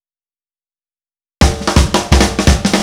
Indie Pop Beat Intro 03.wav